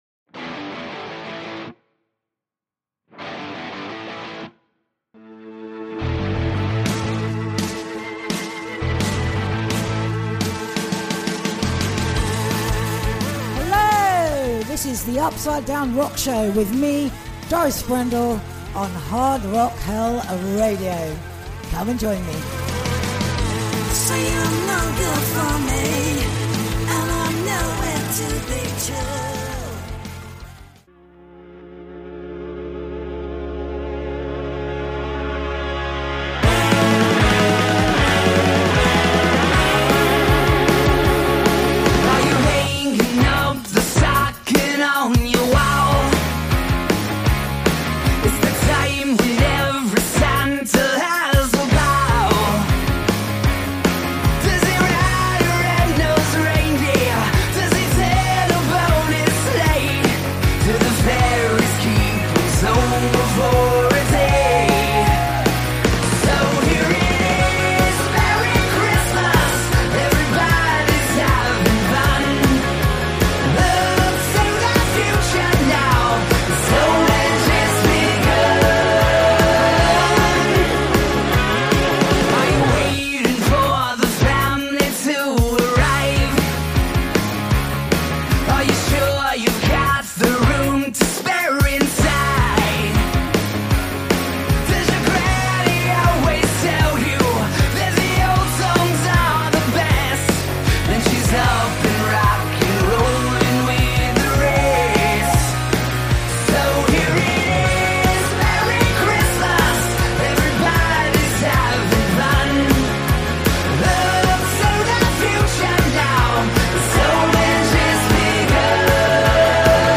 It’s a Christmas special!! Christmas jokes and disaster stories that will make you glad to be alive plus more Xmas rock tunes than you can shake a stick at.